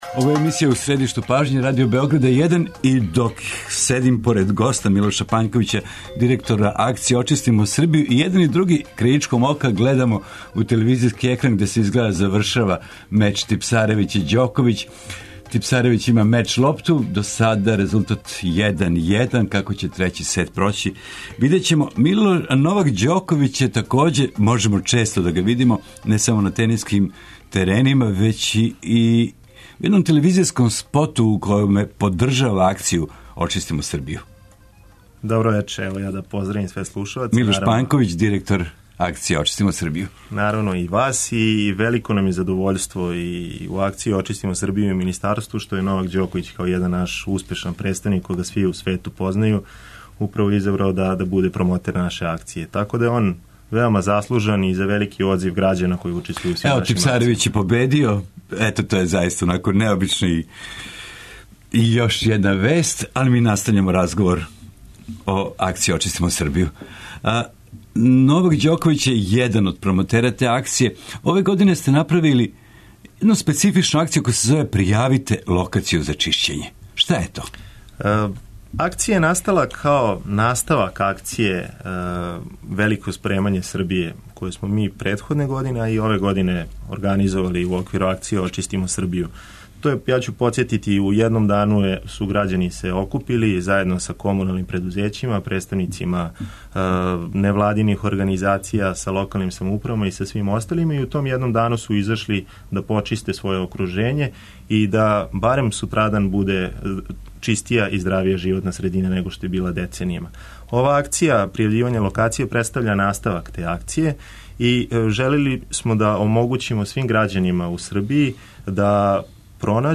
Током емисије и слушаоци ће моћи да кажу шта се још може учинити да би нам Србија била уређенија или да укажу на неку дивљу депонију или сметлиште које би такође требало очистити.